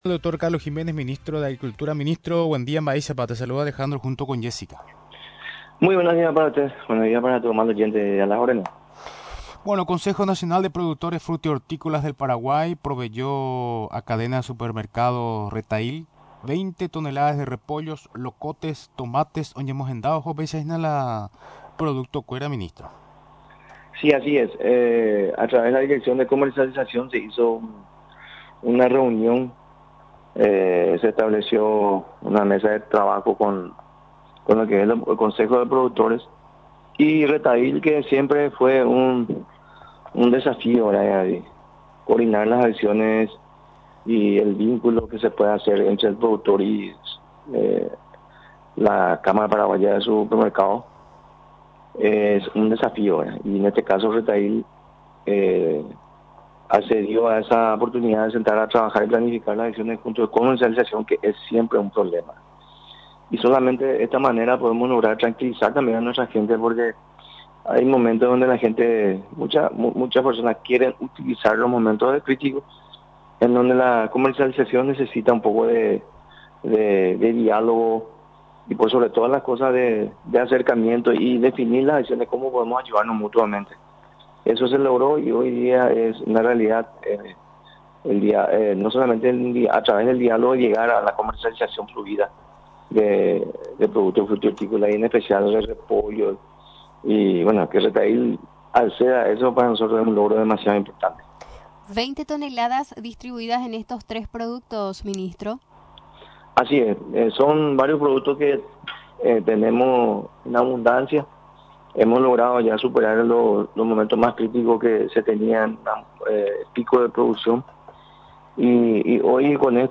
Durante la entrevista en Radio Nacional del Paraguay, el secretario de Estado, explicó los detalles de cómo nació la idea para enviar los mencionados productos frutihortícolas todos venidos del interior del país.